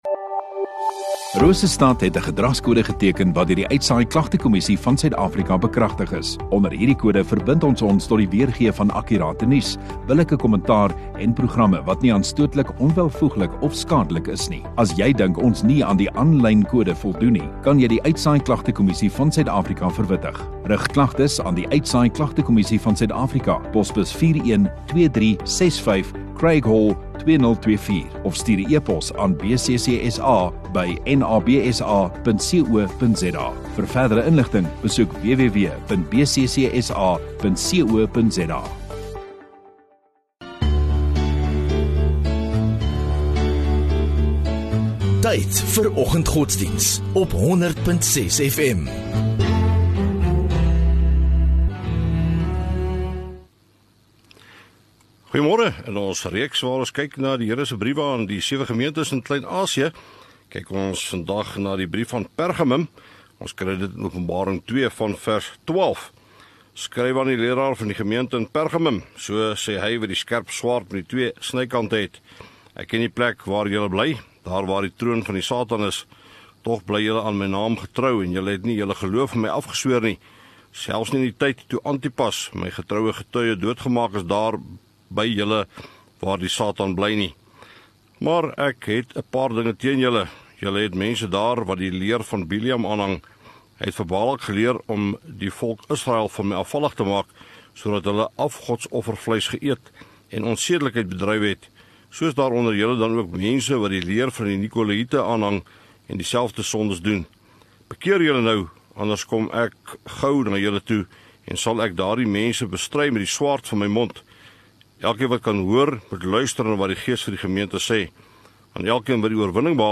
7 May Woensdag Oggenddiens